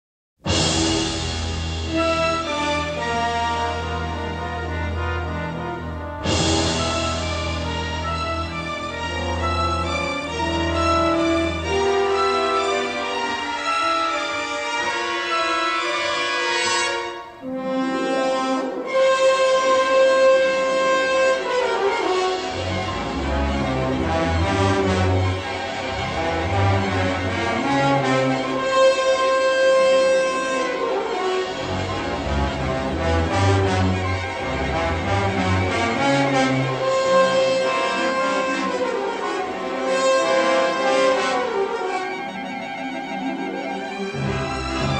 The complete symphonic score is presented in stereo